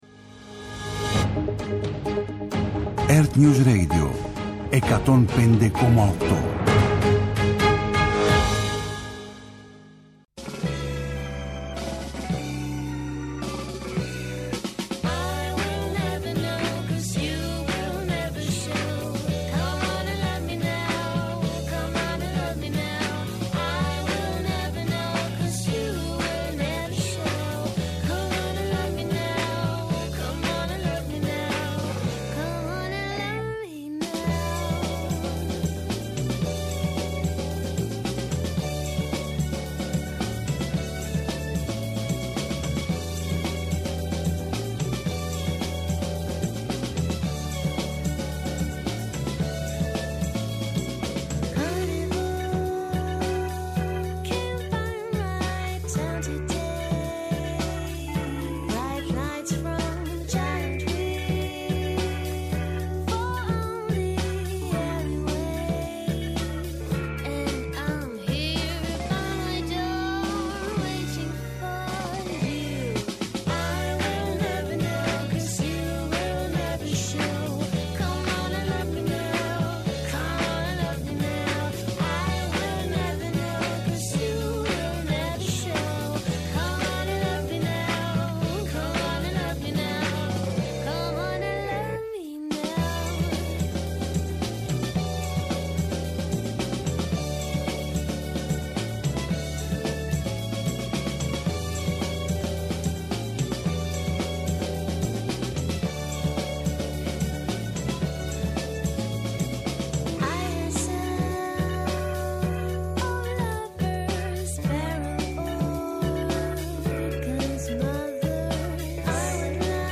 Στο στούντιο